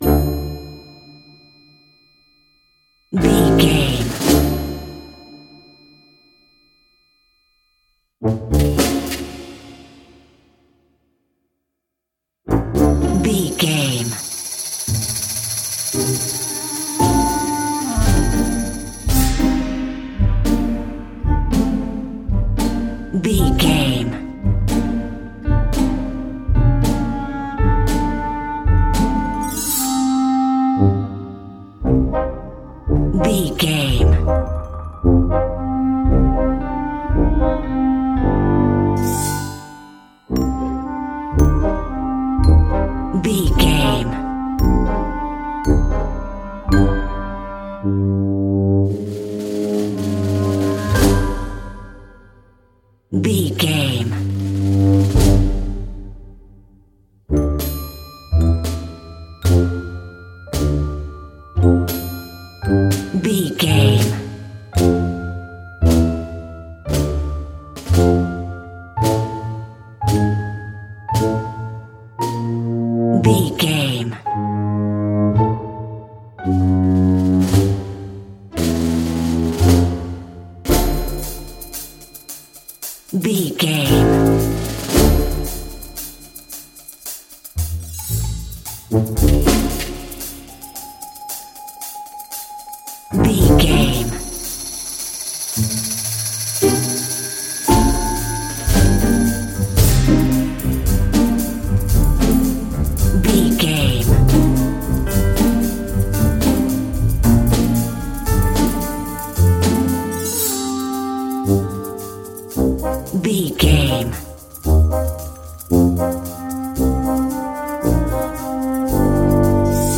Aeolian/Minor
orchestra
percussion
strings
horns
piano
silly
circus
goofy
comical
cheerful
perky
Light hearted
quirky